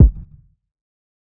TC2 Kicks13.wav